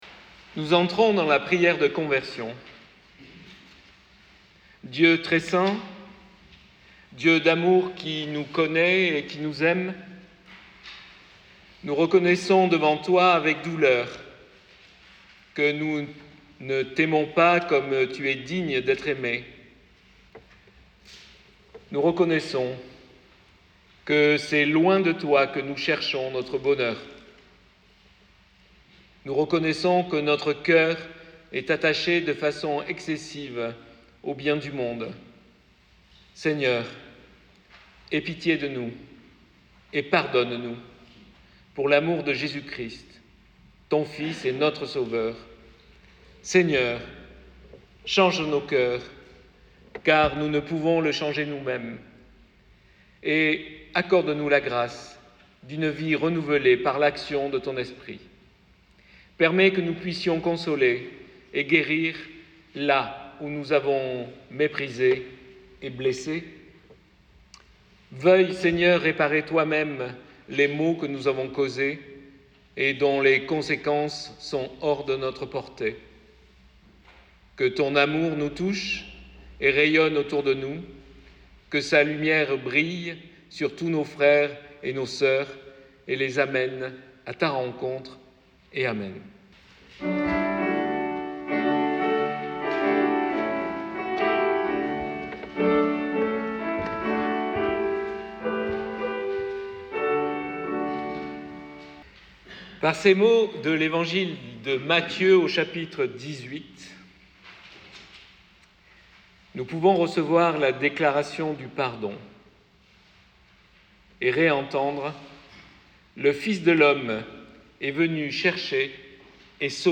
LE CULTE DU 2 MARS 2025. " Qui parle ?"
Prédication du 2 mars 2025 QUI PARLE ?